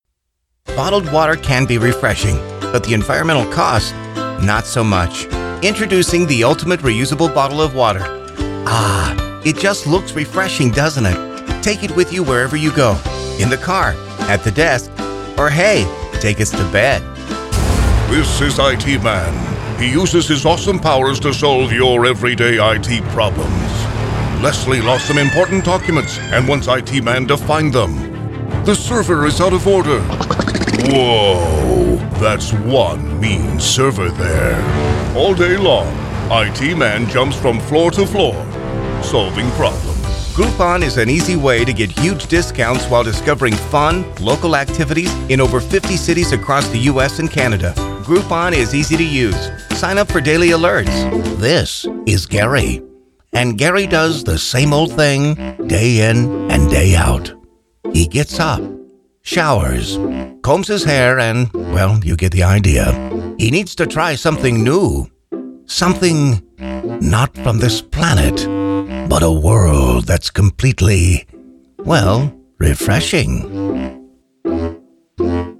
Male
Trans-Atlantic
I have a dynamic voice range, from conversational and relatable, to energetic and powerful announcer.
Microphone: Neumann U87, Sennheiser MKH416